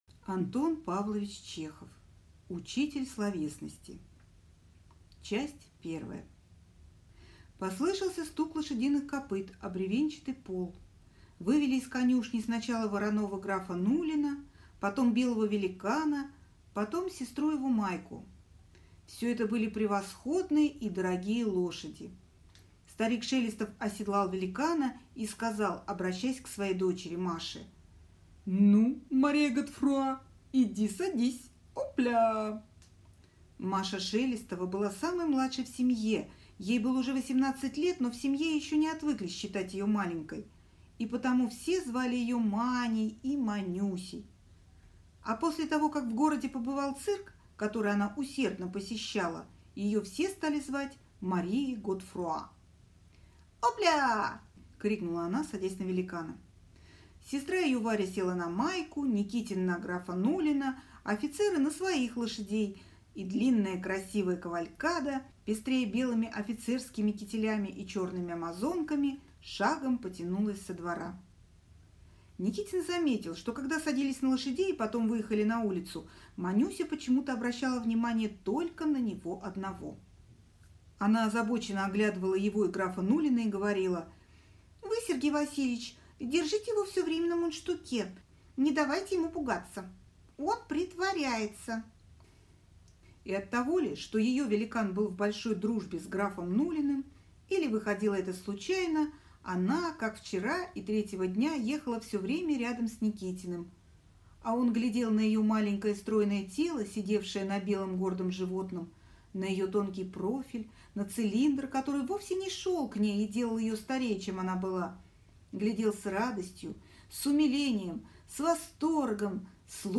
Аудиокнига Учитель словесности | Библиотека аудиокниг